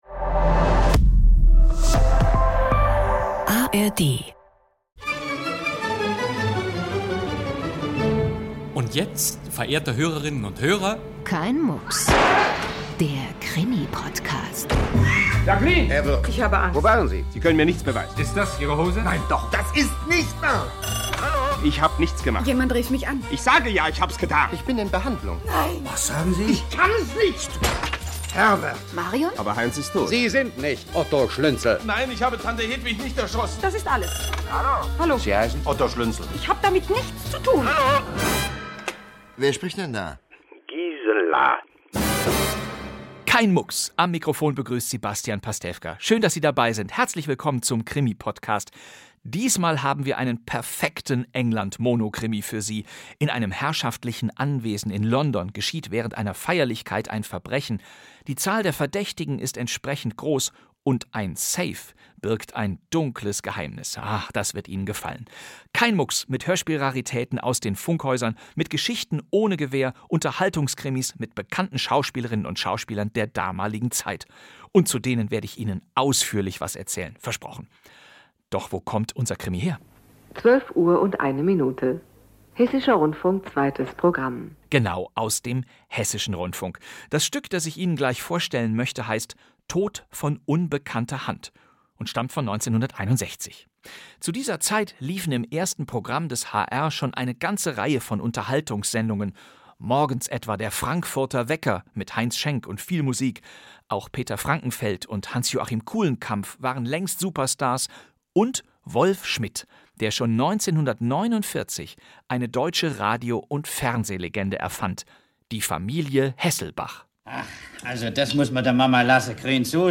Der Tag der Hinrichtung und Die Sache mit der freundlichen Kellnerin. Krimi-Podcast mit Bastian Pastewka - Kein Mucks!